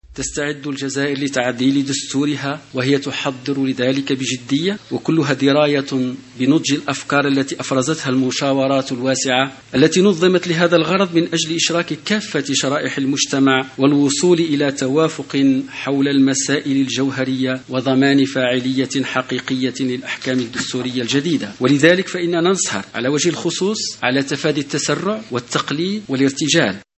الجزائر تحضر بجدية لتعديل الدستور ( الرسالة قرأها المستشار برئاسة الجمهورية محمد علي بوغازي) تثمين جهود الدول الإفريقية في المجال الدستوري و عم بناء المؤسسات ( الرسالة قرأها المستشار برئاسة الجمهورية محمد علي بوغازي)